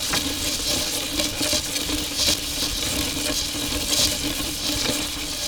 wheel_wood_01.wav